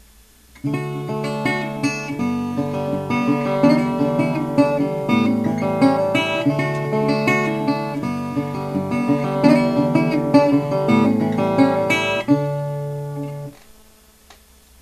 Es ist eine Resonatorgitarre mit Metallkorpus. Der Klang unterscheidet sich erheblich von meiner Dobro – und so war es ja auch gedacht.